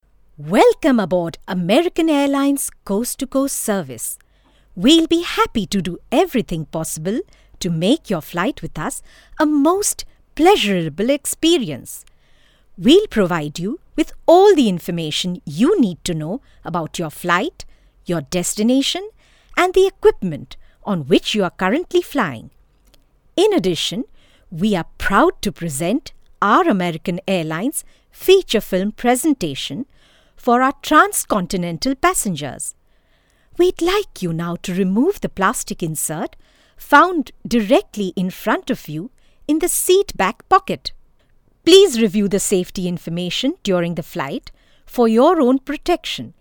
Clear diction and speech. Correct pronunciation, soft, warm, fun, sarcastic. Can modulate different characters.
Sprechprobe: Sonstiges (Muttersprache):